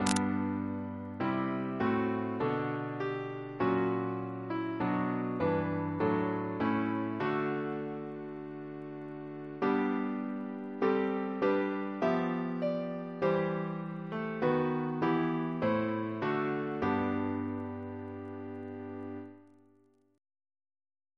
CCP: Chant sampler
Double chant in G Composer: Percy Buck (1871-1947) Reference psalters: RSCM: 25